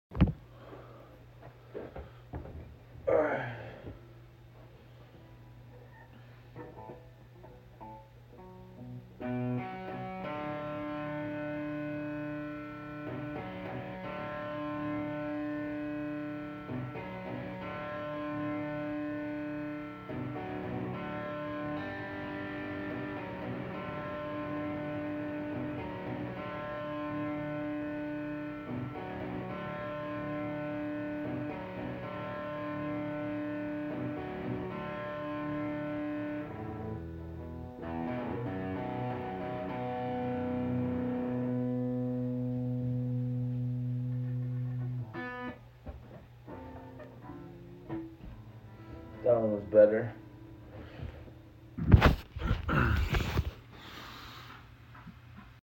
Guitar Practice Sound Effects Free Download
Guitar practice